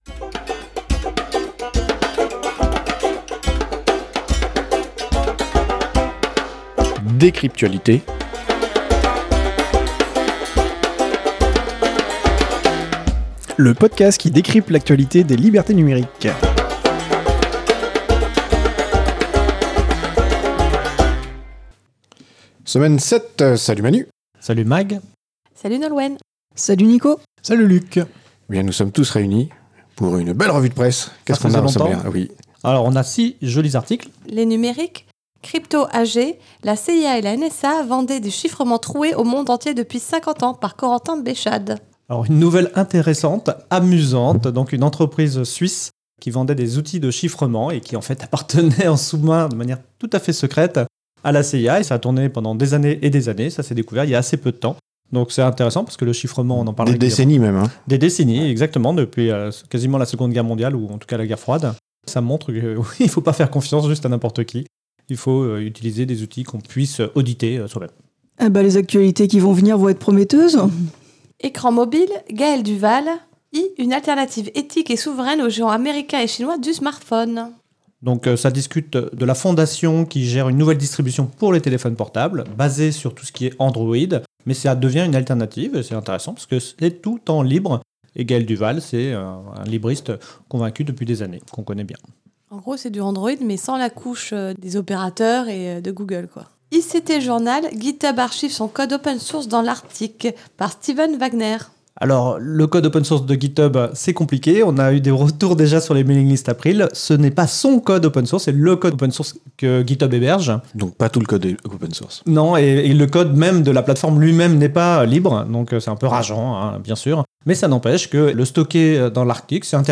Lieu : April - Studio d'enregistrement